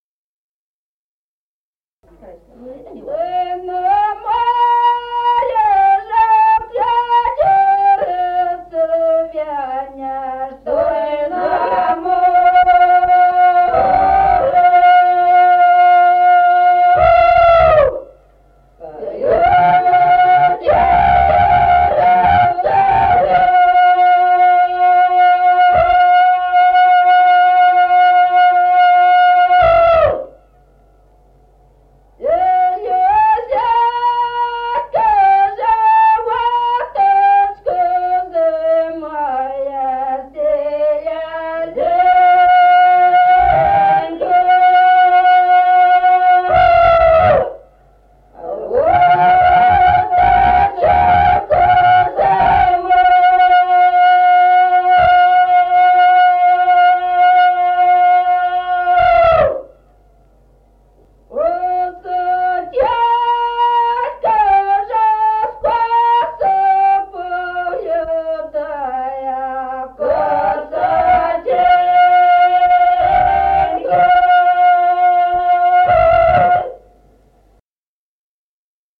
1953 г., с. Остроглядово.